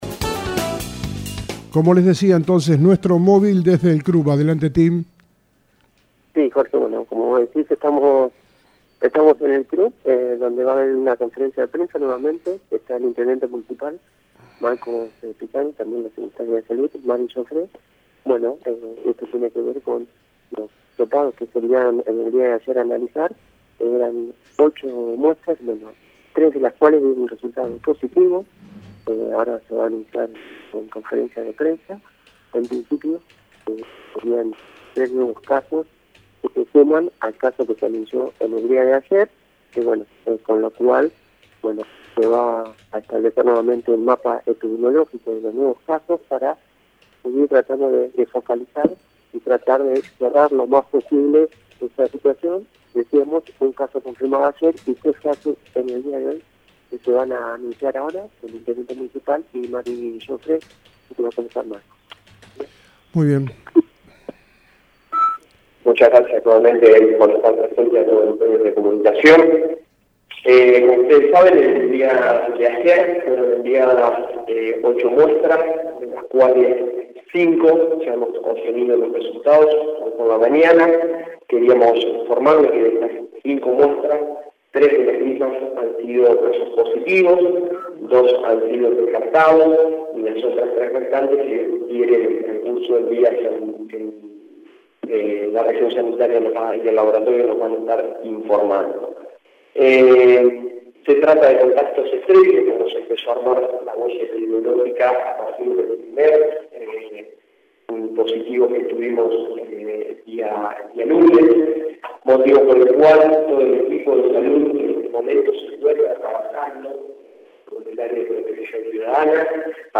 INTENDENTE MARCOS PISANO Y SECRETARIA DE SALUD MARIA E. JOFRE